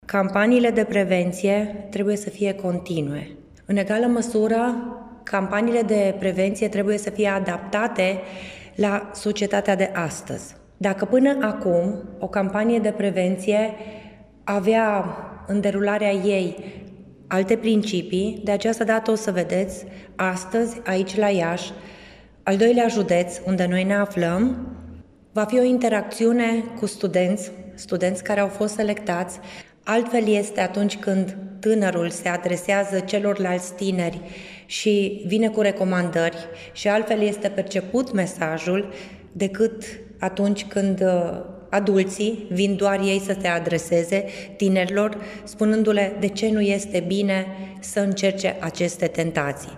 Prezentă la o dezbatere privind combaterea consumului de substanțe interzise ea a subliniat nevoia intesificării campaniilor de prevenție în acest domeniu și structurarea lor pe grupe de vârstă.